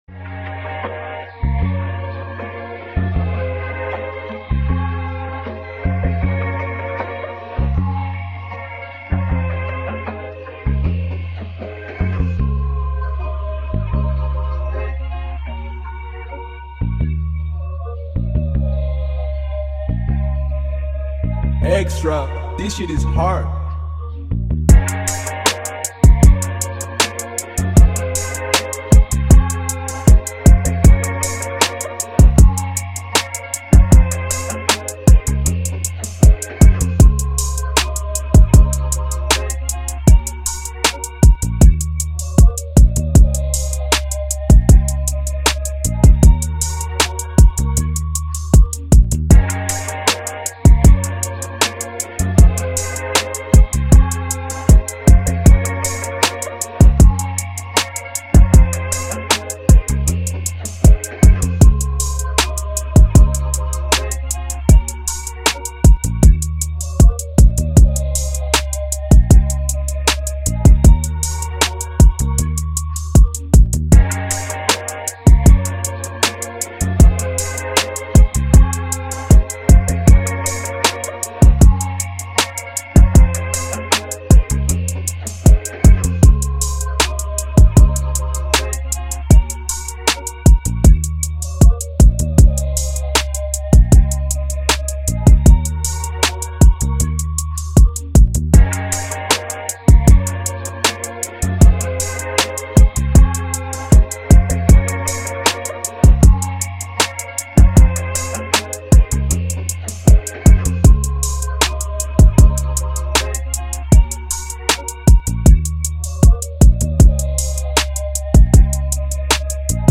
pumped-up, energetic new collaboration